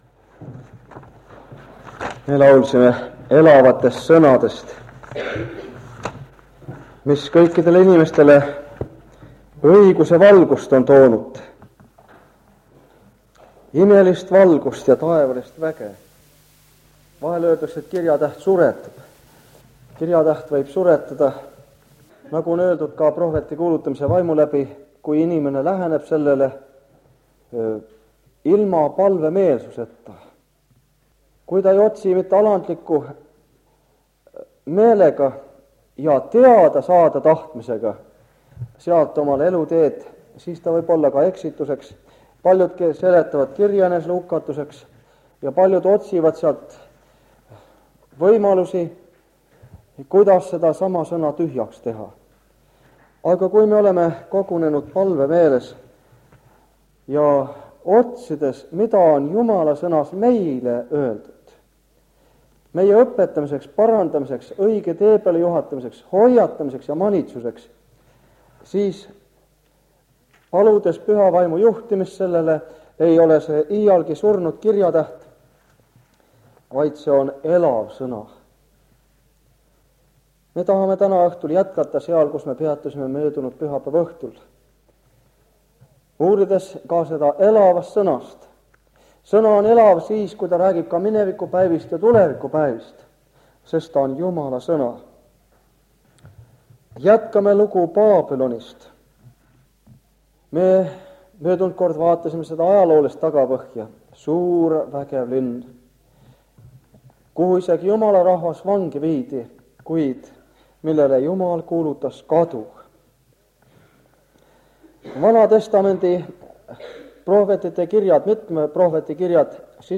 Jutlused
Jutluste minisari Kingissepa adventkoguduses 1978 aastal.